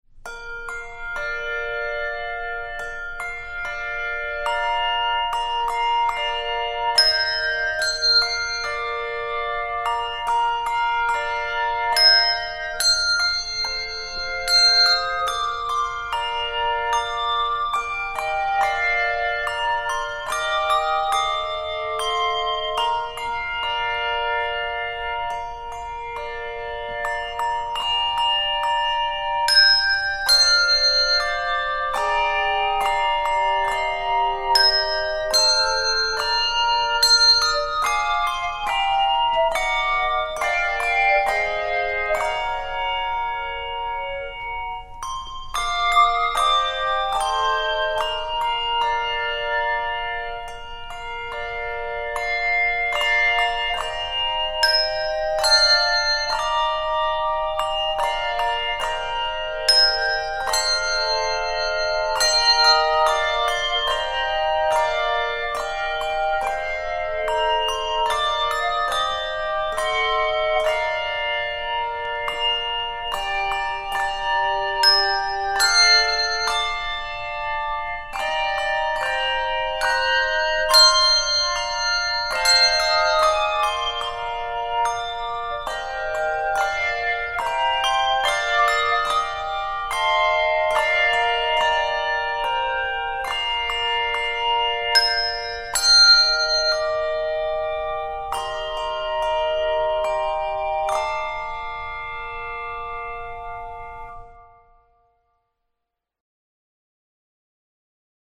relaxed arrangement